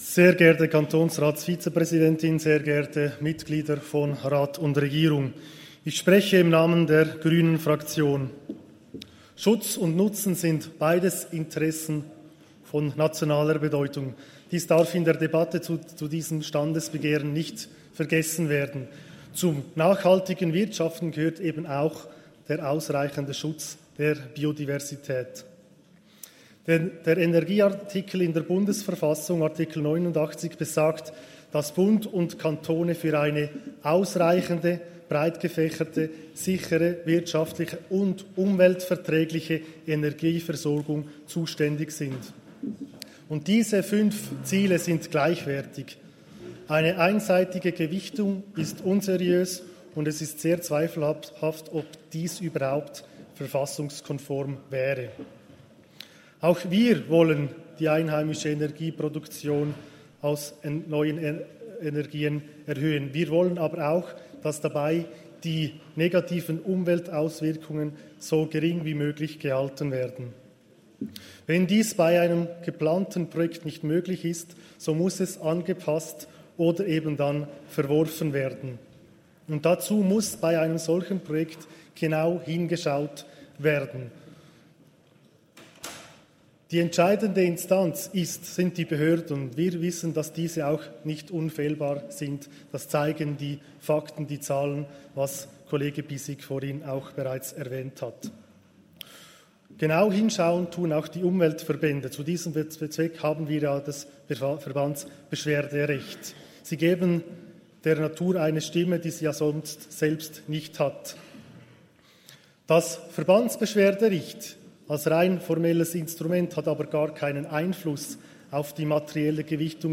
Session des Kantonsrates vom 18. bis 20. September 2023, Herbstsession
Bosshard-St.Gallen (im Namen der GRÜNE-Fraktion): Auf das Standesbegehren ist nicht einzutreten.